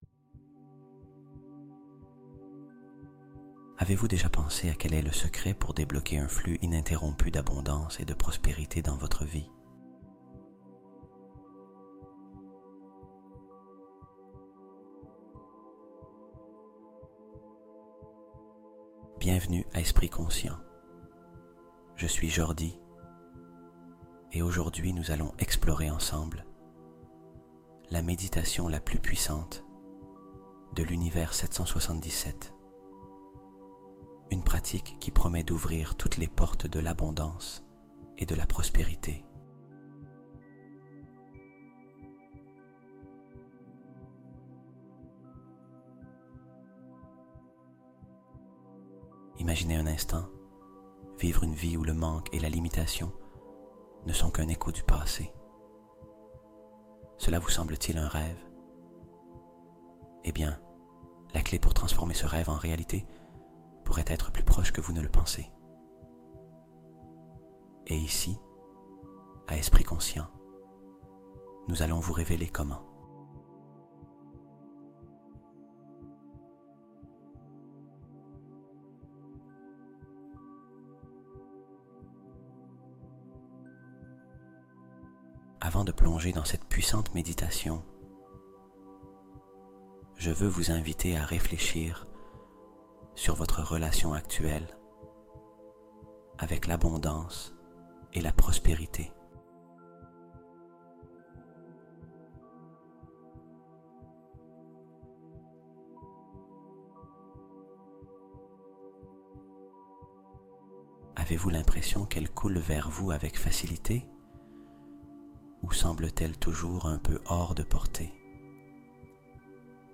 Méditation 777 : la fréquence interdite qui ouvre les coffres de l'abondance